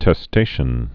(tĕ-stāshən)